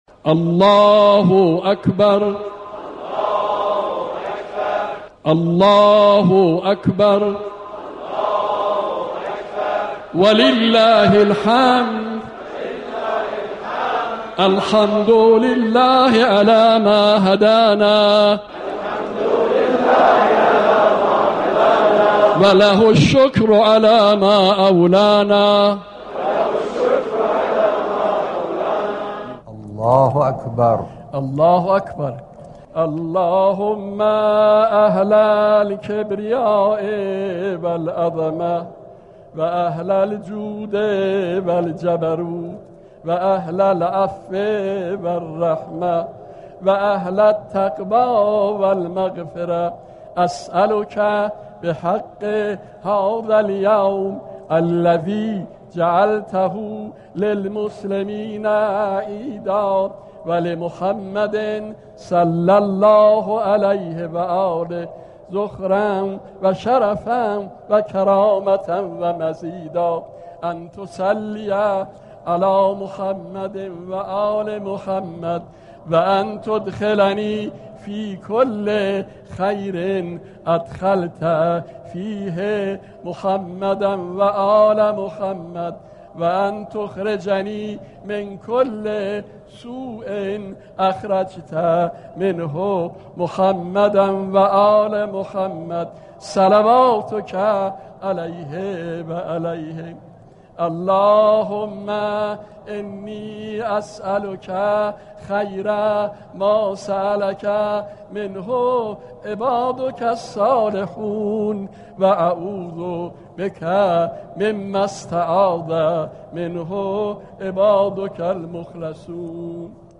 پخش زنده "نماز عید فطر" از رادیو تهران/ متن و صوت قنوت
شبكه رادیویی تهران با استقرار واحد سیار خود به صورت زنده نماز باشكوه عید فطر را از مسجد امام حسین(ع) پوشش می دهد.